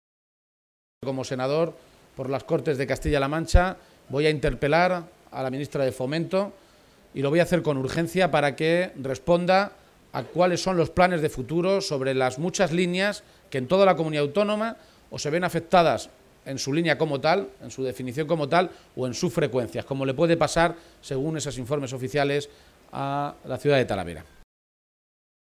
El secretario general del PSOE de Castilla-La Mancha, Emiliano García-Page, ha participado hoy en la tradicional Feria de San Isidro de Talavera de la Reina, y ha aprovechado su visita a la Ciudad de la Cerámica para compartir un encuentro con los medios de comunicación en el que ha repasado las cuestiones de actualidad regional.
Cortes de audio de la rueda de prensa